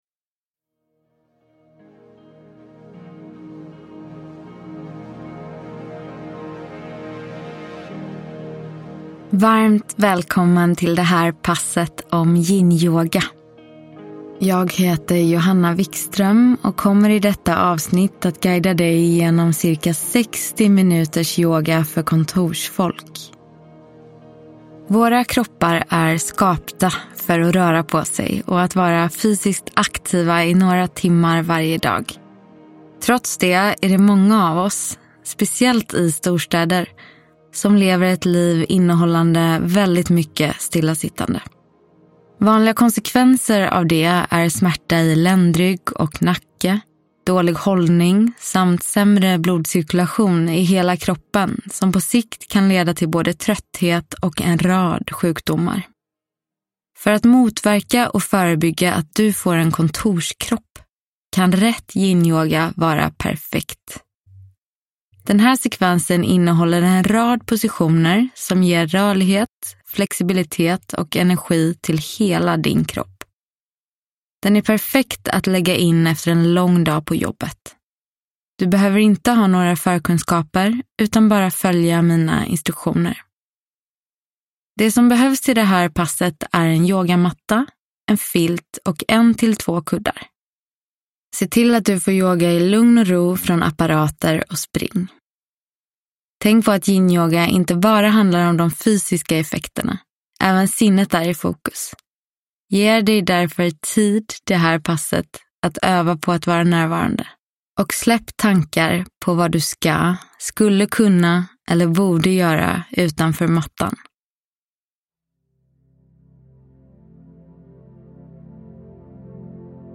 Yinyoga - Pass för kontorsfolk – Ljudbok